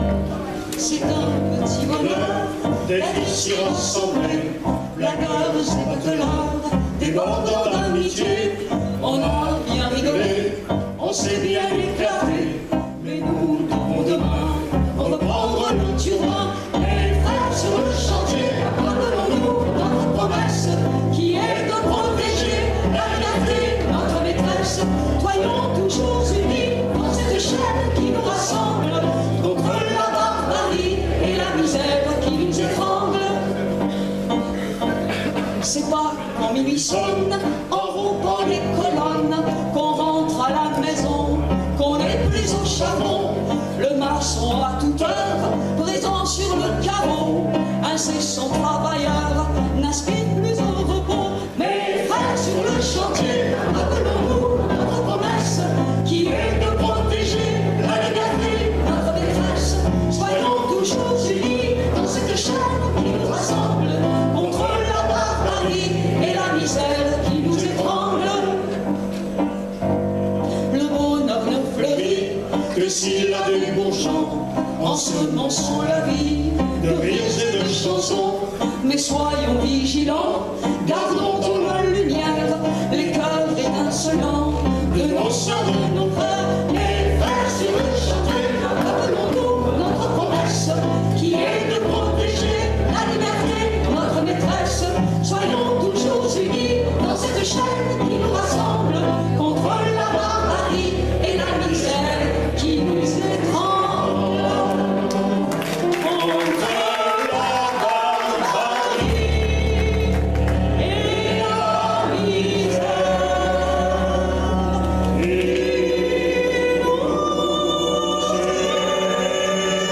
Enregistrement public, Festival 2017